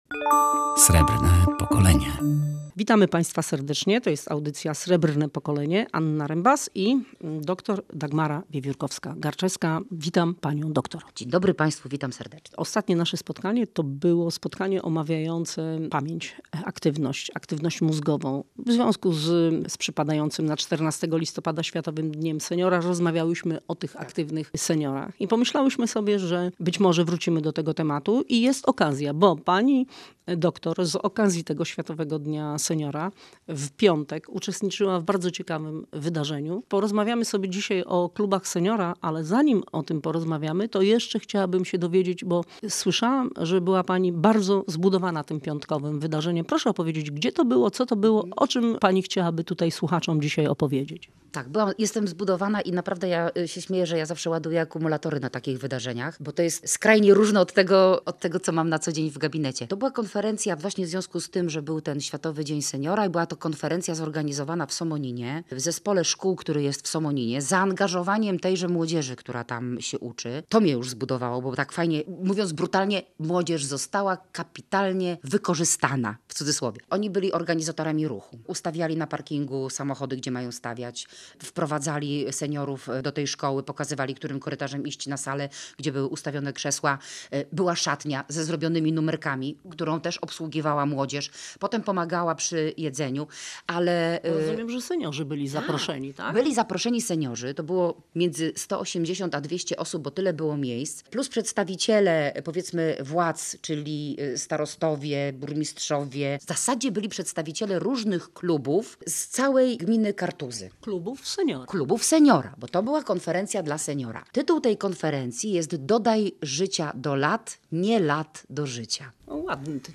Z tego powodu panie opowiadały także o świetlicach dziennych.